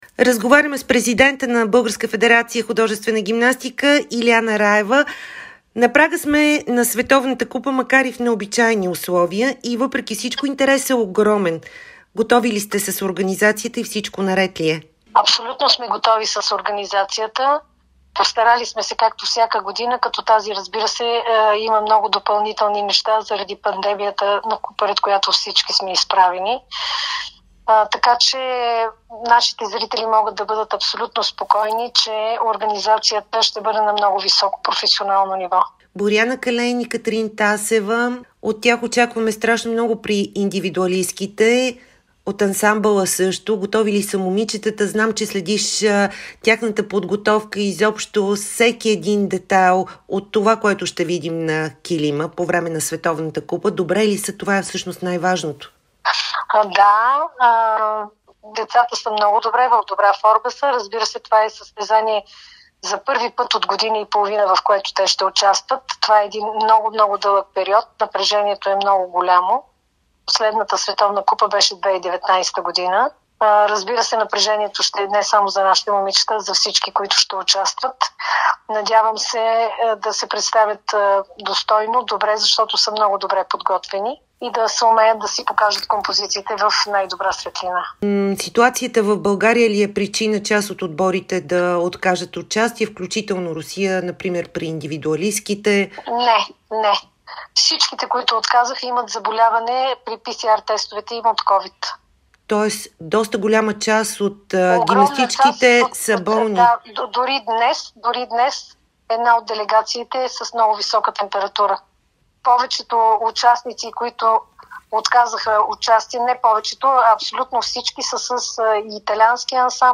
Положителни тестове за коронавирус и заболявания са причина много отбори начело с Русия да откажат Световната купа по художествена гимнастика в София, призна ексклузивно за Дарик и Dsport президентът на БФХГ Илиана Раева.